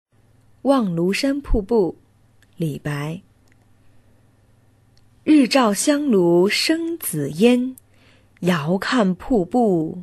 五年级语文下册课文朗读 望庐山瀑布 4（语文a版）_21世纪教育网-二一教育